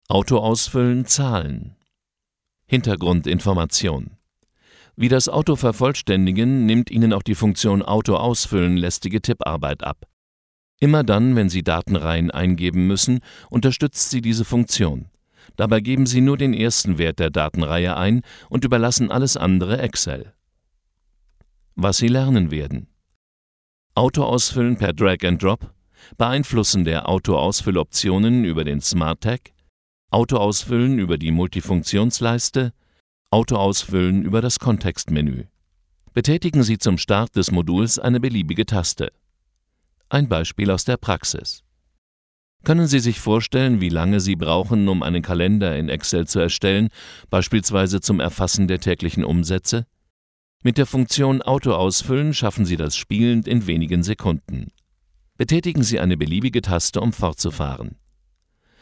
Sprechprobe: Sonstiges (Muttersprache):
German voice over artist with more than 30 years of experience.